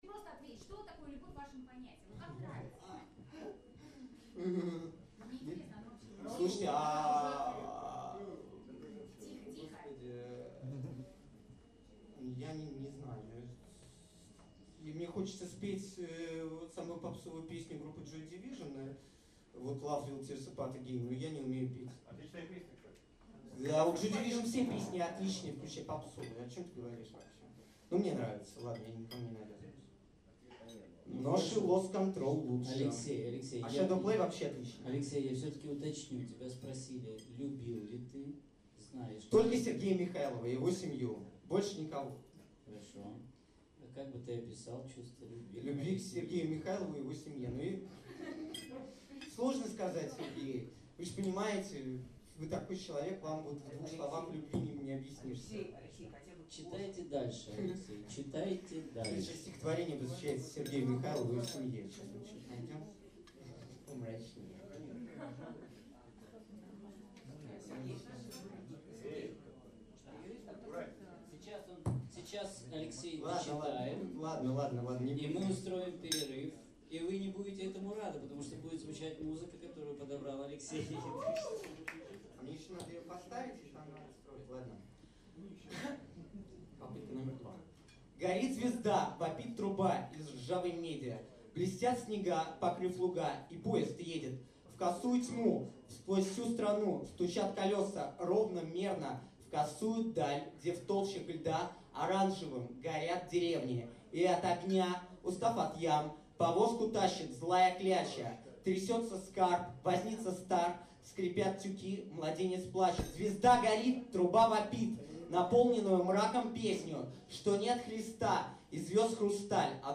Diskussion Liebe
die lebendige und zum Teil wilde, absurde Diskussion verfolgte dieses Ziel hartnäckig, aber letztlich war der Abend gerade deswegen gelungen (und also nicht ungemütlich). man wünschte sich solche verbalen Saalkeilereien einmal bei deutschen Lesungen. ein Hoch auf alle, die sich so um die Sprache streiten!